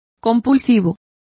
Complete with pronunciation of the translation of compulsory.